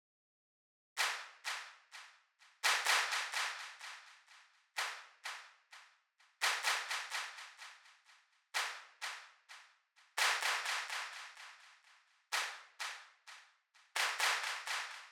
handsclap_ladies_delay.wav